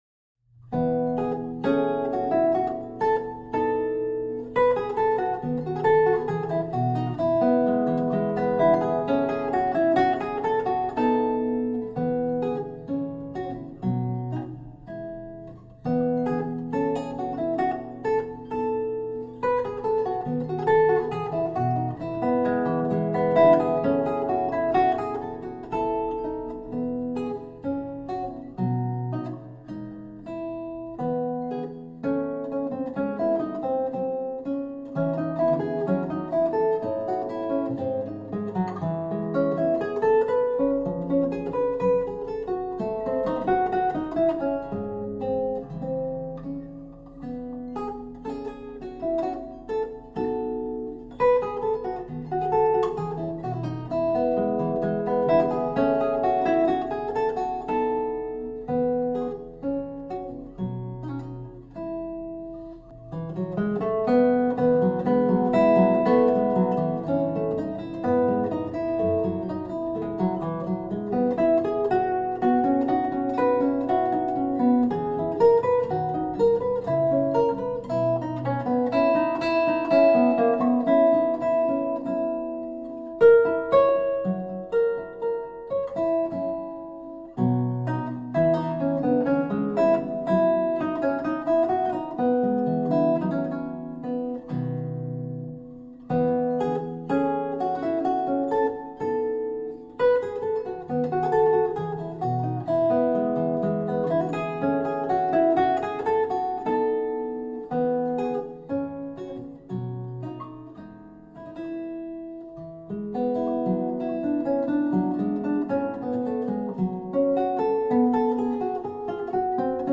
Montreal Classical Guitarist 1
Montreal-Classical-Guitarist-1-Gavotte.mp3